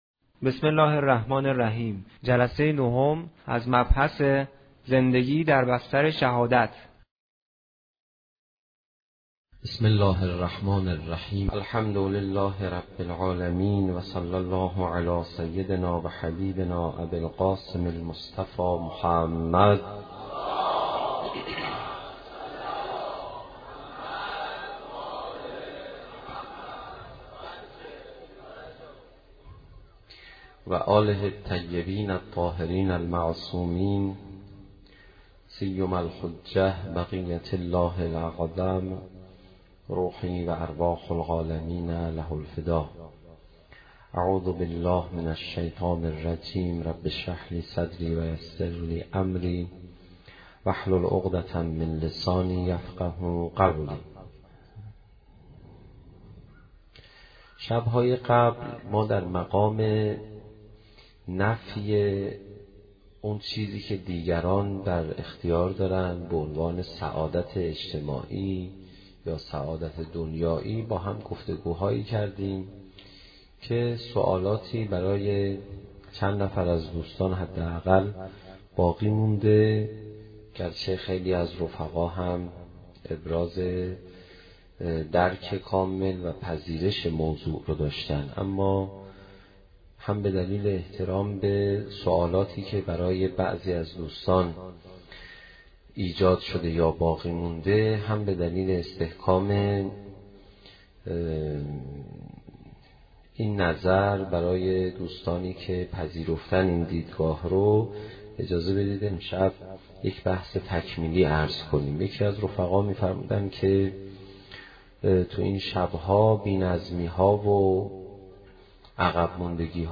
سخنرانی حجت الاسلام پناهیان درمورد زندگی در بستر شهادت
سخنرانی حاج آقای پناهیان با موضوع زندگی در بستر شهادت